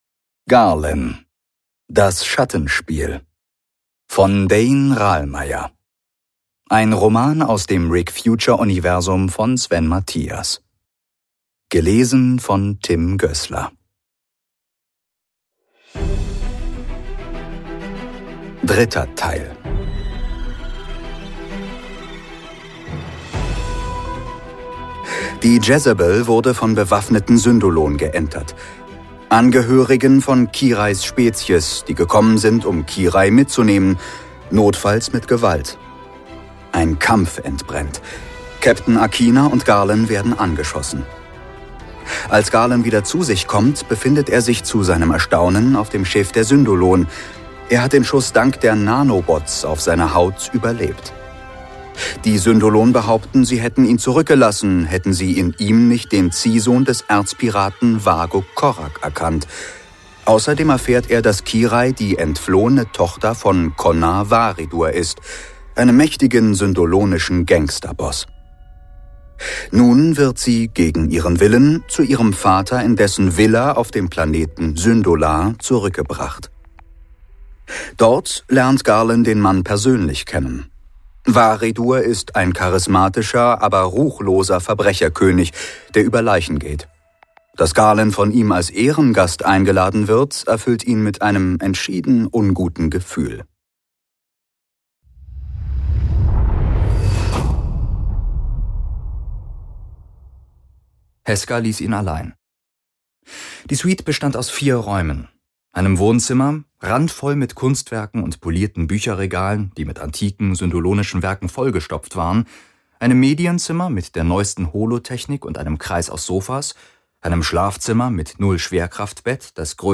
Rick Future - Die SciFi-Hörspielserie